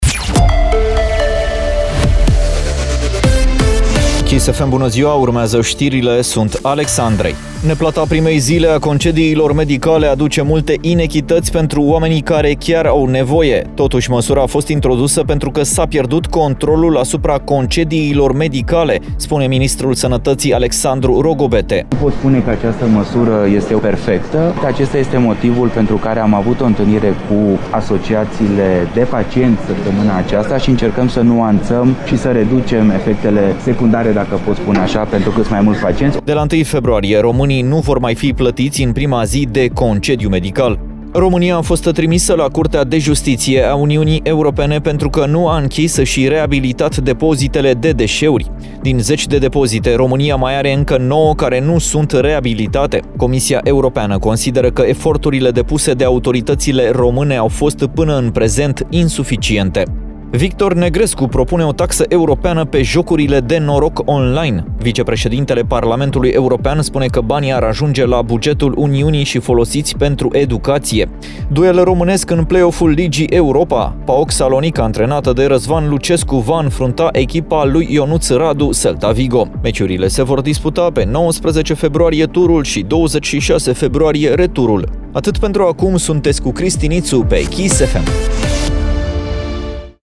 Știrile zilei de la Kiss FM - Știrile zilei de la Kiss FM